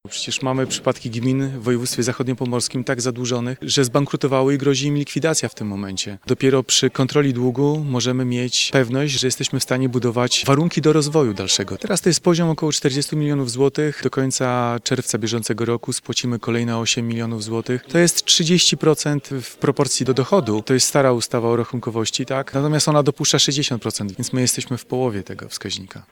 – To oczywiście cieszy, ale najważniejsze jest dla mnie to, w jaki sposób kontrolowane jest zadłużenie gminy – mówi Robert Krupowicz.